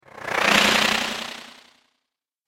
دانلود صدای حشره 21 از ساعد نیوز با لینک مستقیم و کیفیت بالا
جلوه های صوتی
برچسب: دانلود آهنگ های افکت صوتی انسان و موجودات زنده دانلود آلبوم صدای انواع حشرات از افکت صوتی انسان و موجودات زنده